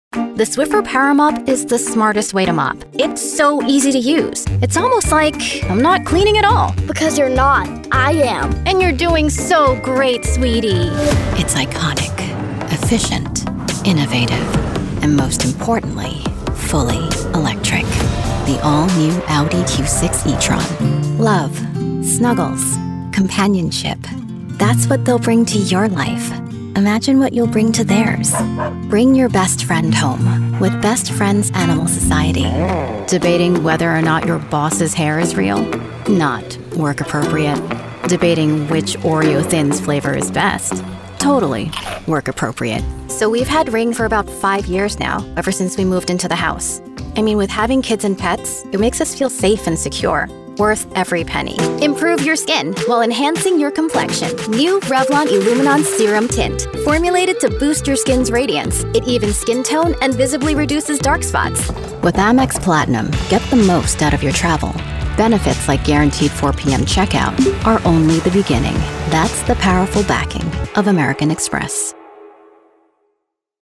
Anglais (Américain)
Jeune, Amicale, Chaude, Enjouée, Accessible, Fiable
Commercial